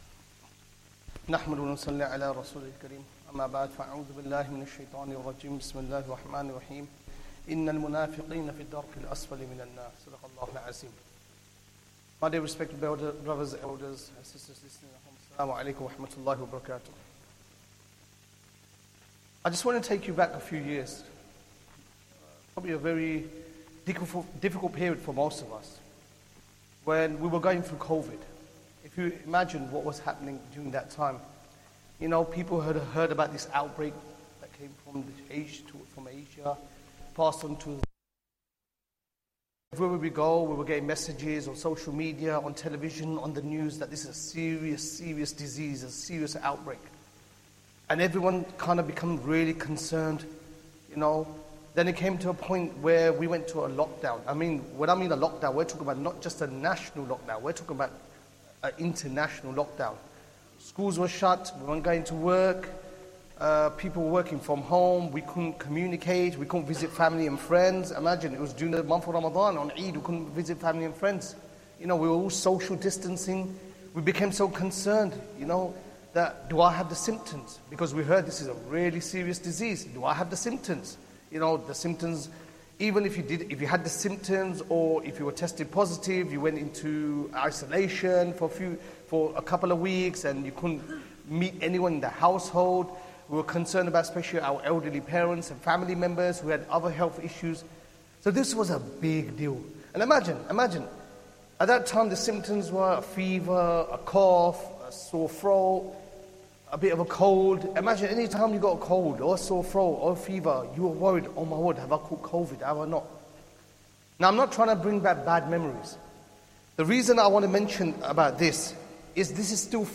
Ramadhan Day 11 Asr Talk
Masjid Adam, Ilford